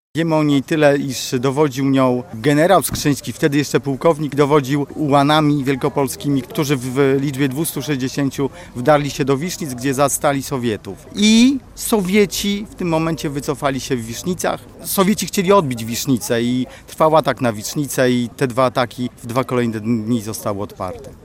– Potyczkę, jaka miała miejsce w Wisznicach niewiele osób łączy z Bitwą Warszawską – mówi w rozmowie z Radiem Lublin wójt gminy Wisznice, Piotr Dragan.